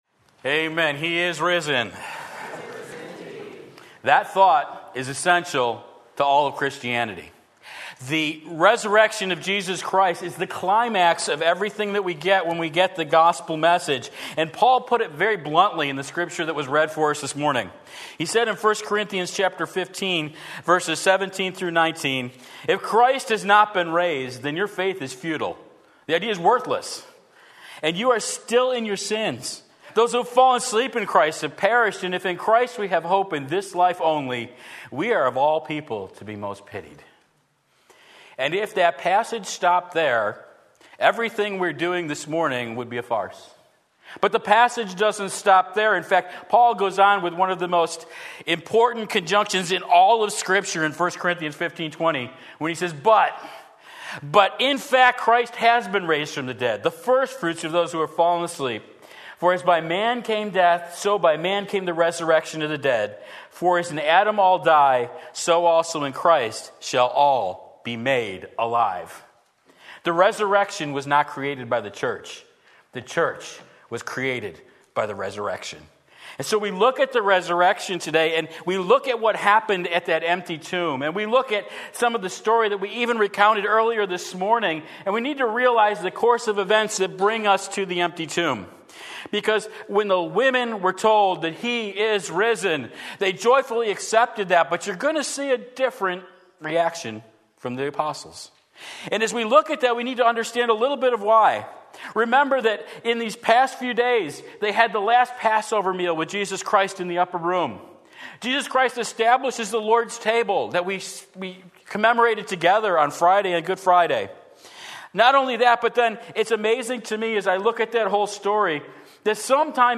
Sermon Link
Jesus Opens Their Minds Luke 24:36-49 Sunday Morning Service